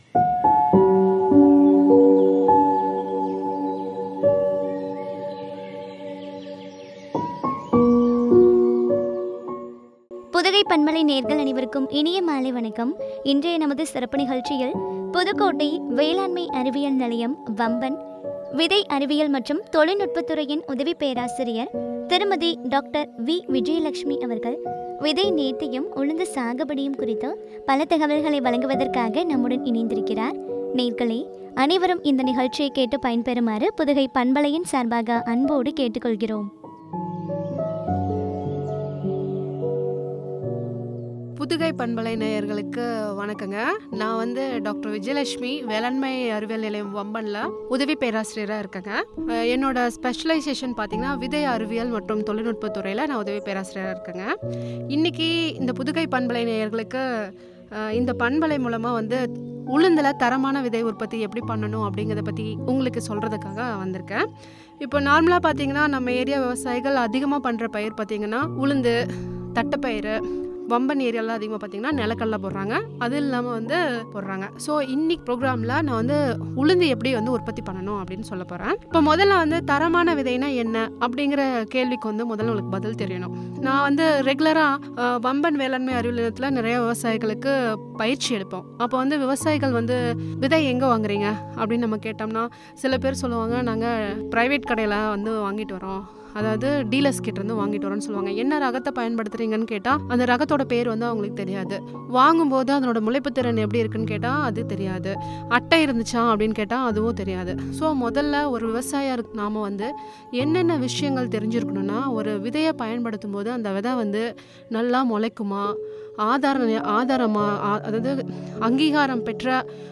விதை நேர்த்தியும், உளுந்து சாகுபடியும் பற்றிய உரையாடல்